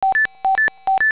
dadit-dadit-dadit) prima del rogerbeep (
Besetztzeichen.wav